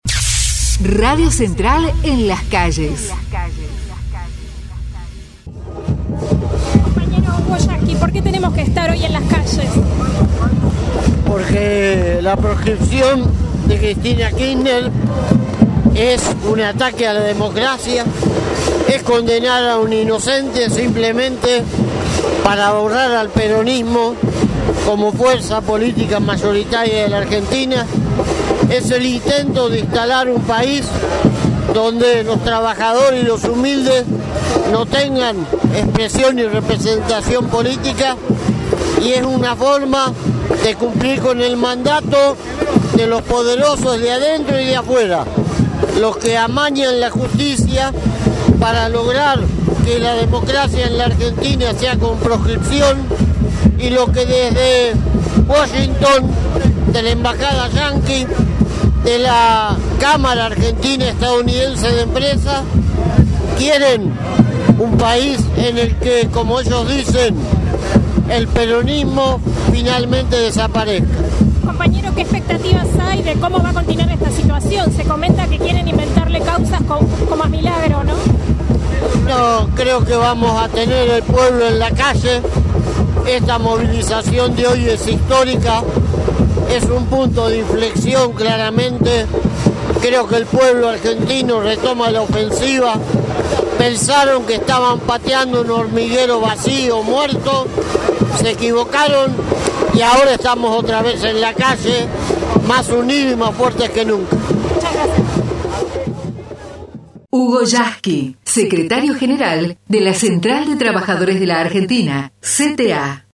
HUGO YASKY en la movilización contra la proscripción política a Cristina Cristina Fernández de Kirchner Red Sindical Internacional de Solidaridad y de Luchas - ¡Solidaridad con el pueblo argentino!
yasky_marcha_x_cristina_18_junio.mp3